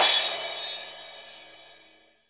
JV_CYMB_L.wav